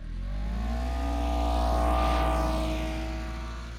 Internal Combustion Subjective Noise Event Audio File (WAV)